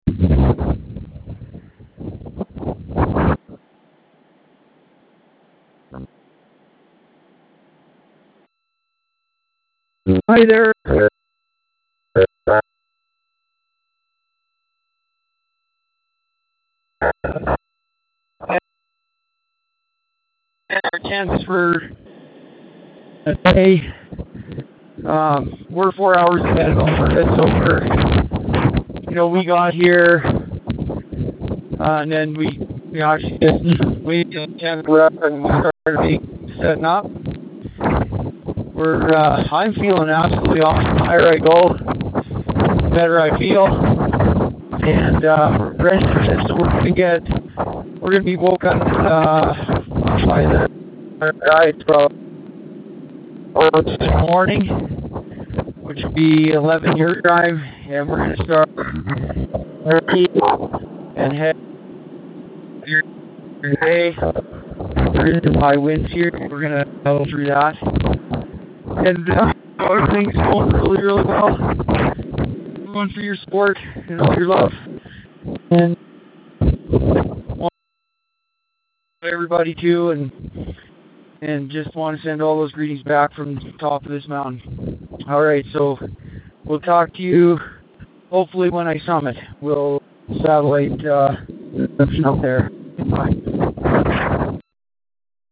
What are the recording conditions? ( strong wind on the background – difficult connection)